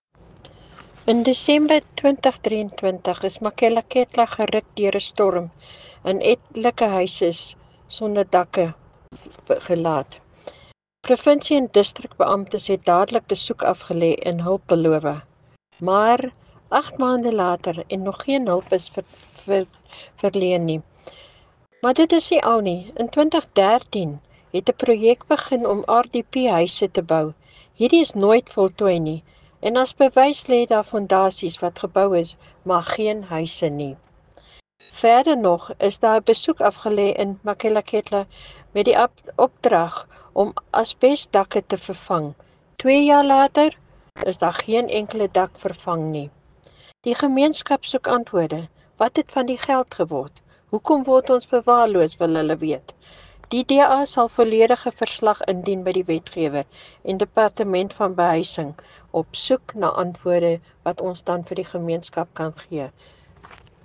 Afrikaans soundbites by Cllr Brunhilde Rossouw and